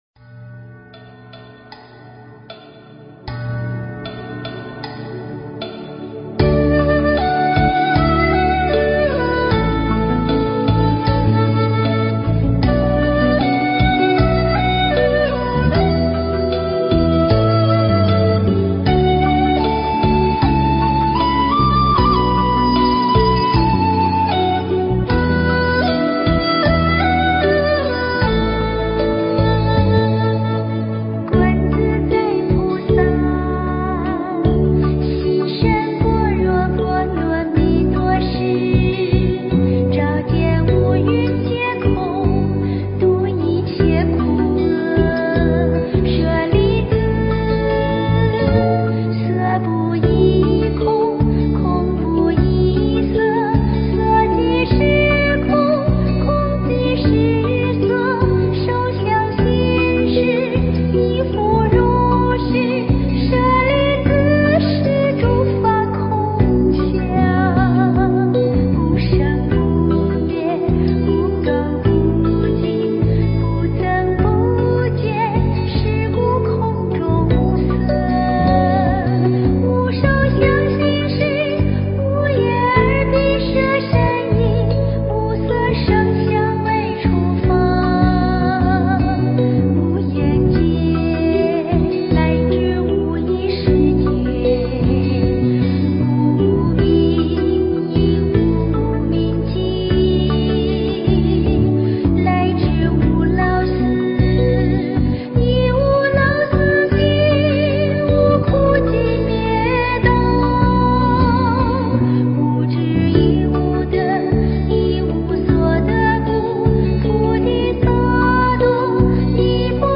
标签: 佛音 诵经 佛教音乐